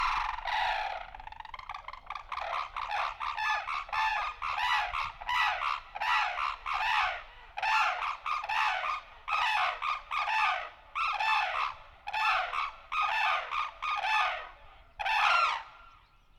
Unison Call | A duet performed by a pair, to strengthen their bond and protect their territory.
Sarus-Crane-Unison-.mp3